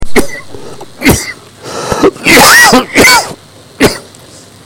咳声紧闷.mp3